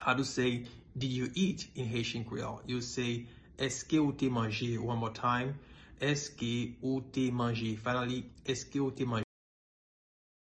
Did-you-eat-in-Haitian-Creole-Eske-ou-te-manje-pronunciation-by-a-Haitian-Creole-teacher.mp3